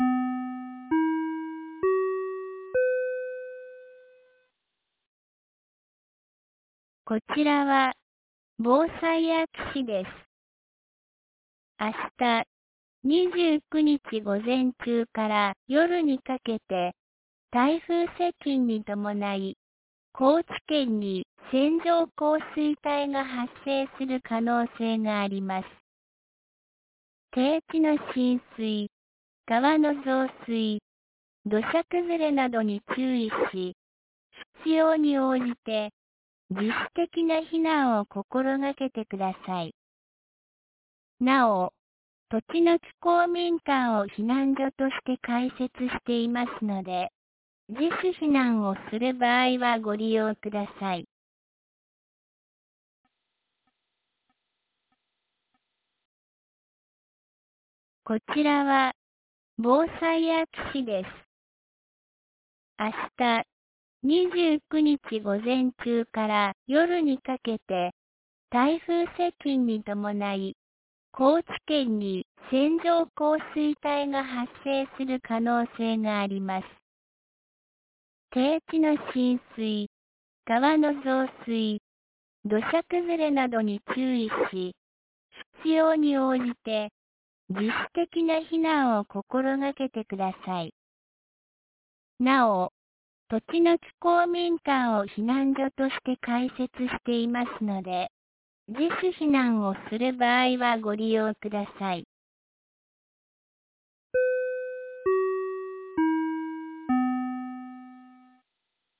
2024年08月28日 16時06分に、安芸市より栃ノ木へ放送がありました。